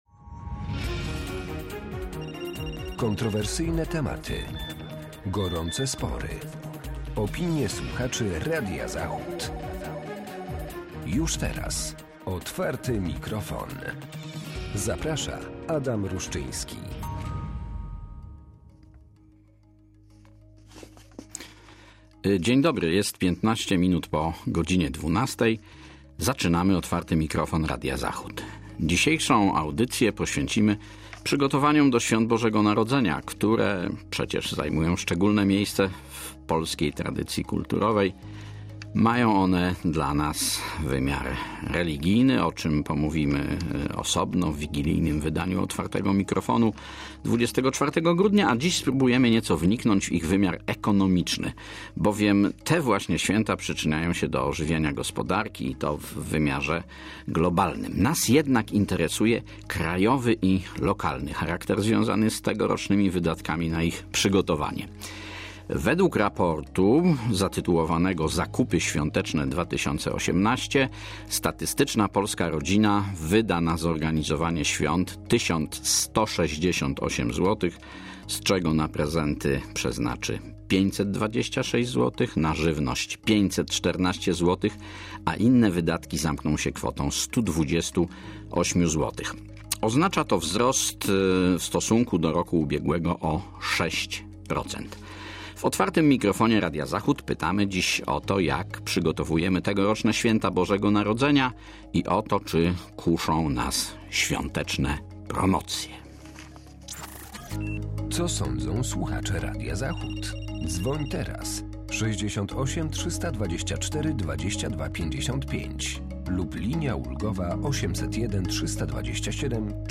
Od godz. 12:12 czekamy na Państwa telefony